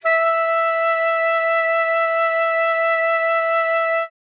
42e-sax10-e5.wav